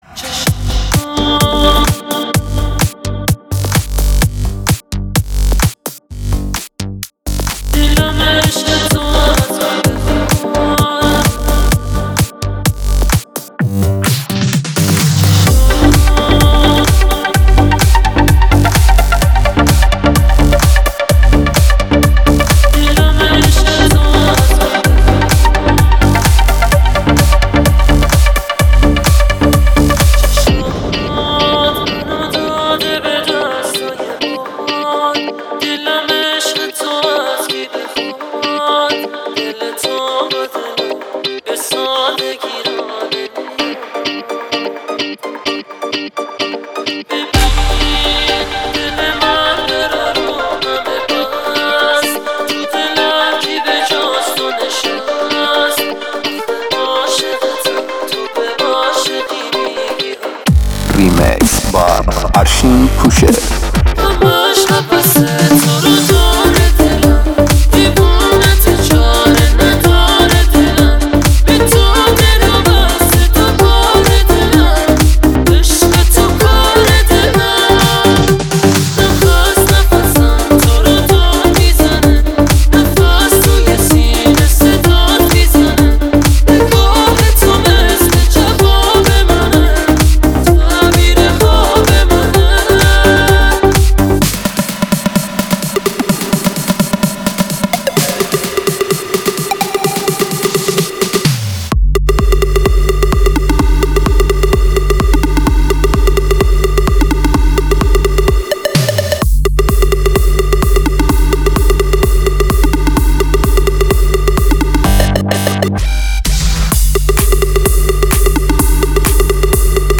شاد بیس دار تریبال